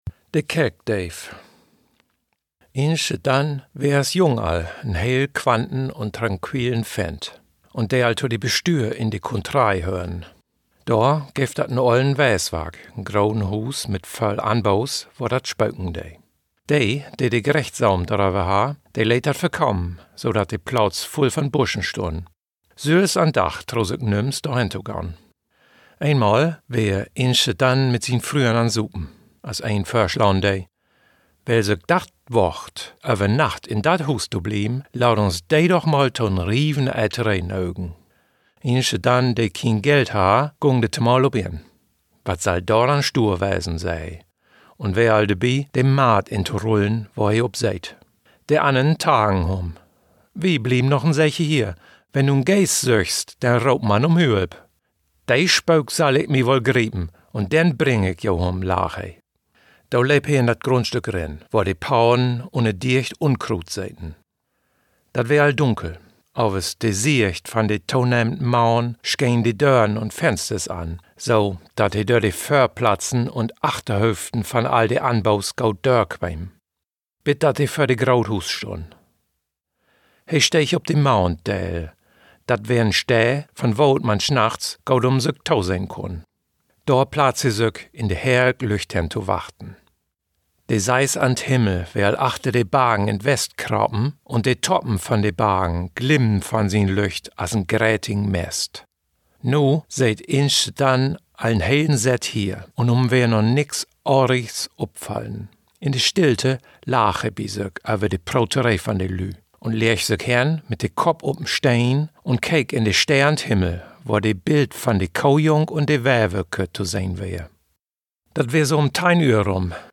Plattdeutsch, Oostfreesk, Platt